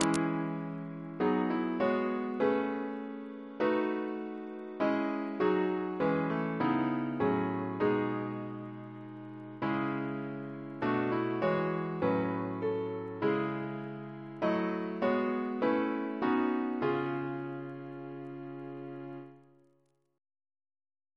Double chant in C♯ minor Composer: Richard Wayne Dirksen (1921-2003), Organist of Washington Cathedral Note: for Psalm 23 Reference psalters: ACP: 217